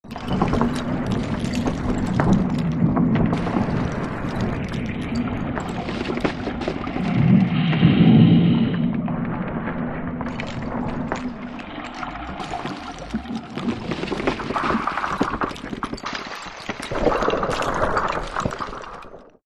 Звук грузового судна плывущего между айсбергами